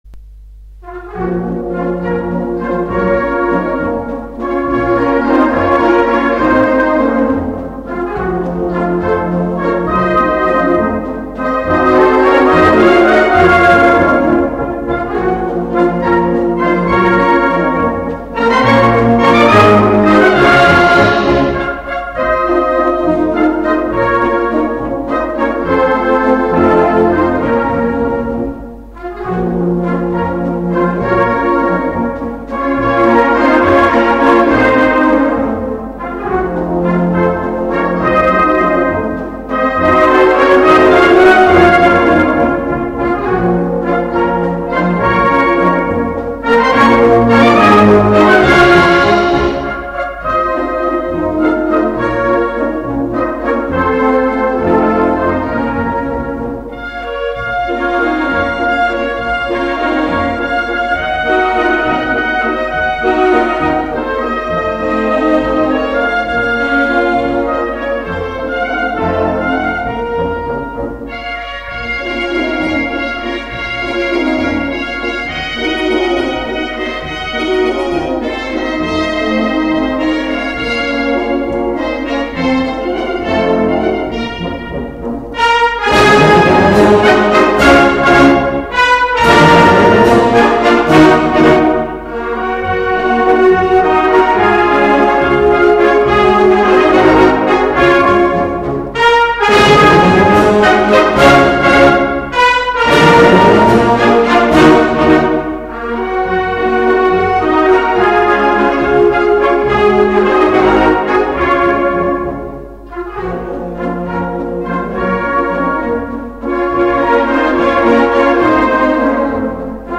Да и классическое духовое исполнение более чем устраивает.